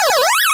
The sound Galaxip makes in Namco Roulette in SSBU